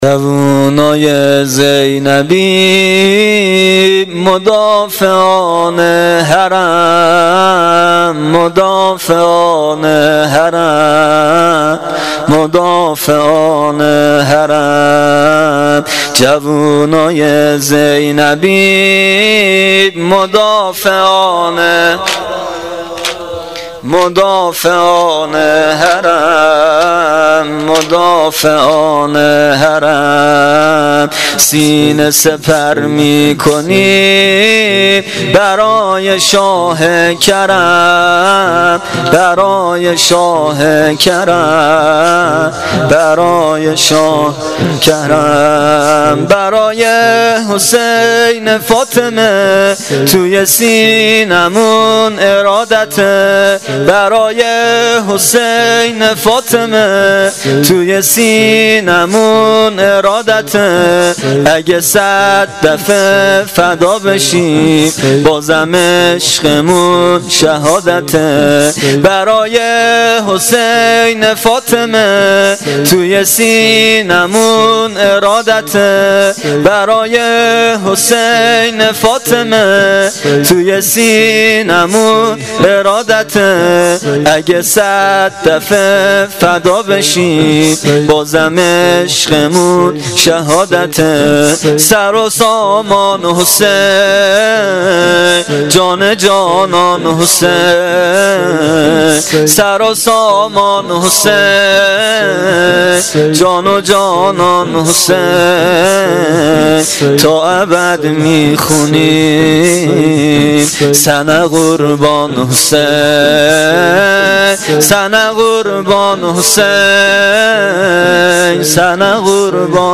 زمینه شب چهارم محرم الحرام 1396
شب چهارم محرم, روضه